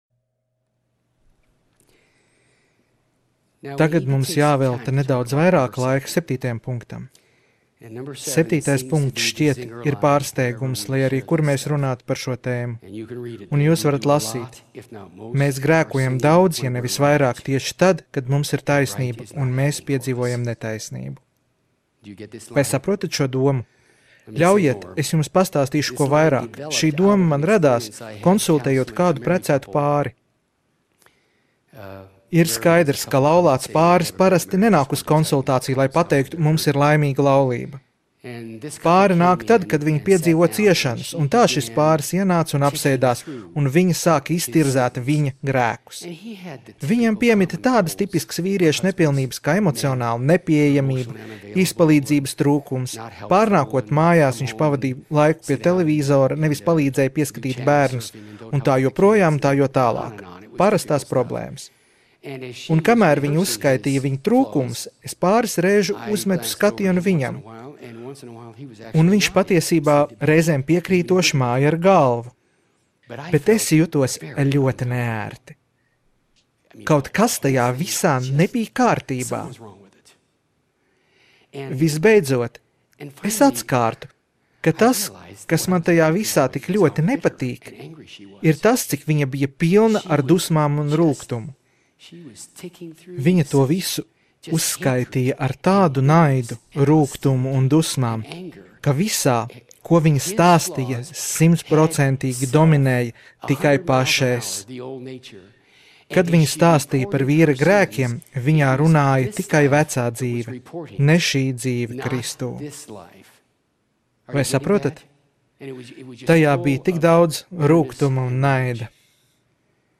Skatīties video Seminārs - Kā pareizi nomirt... un dzīvot, lai par to stāstītu!